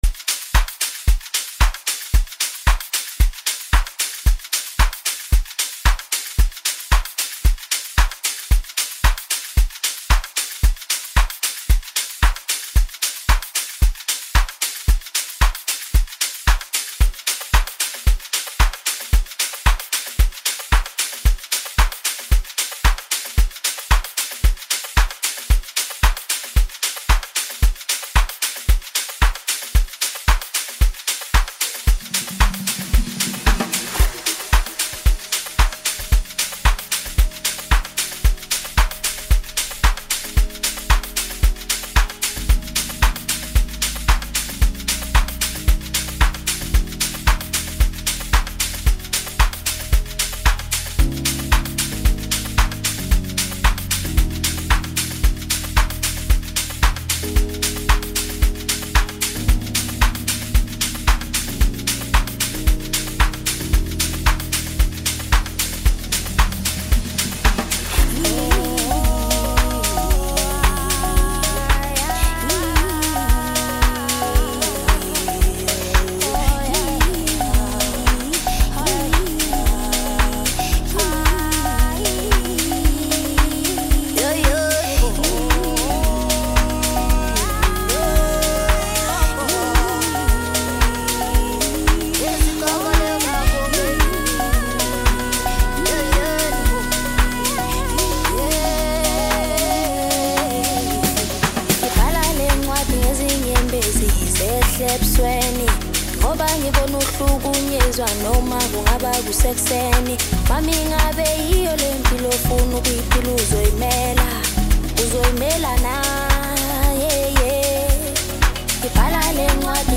Home » Amapiano » DJ Mix » Hip Hop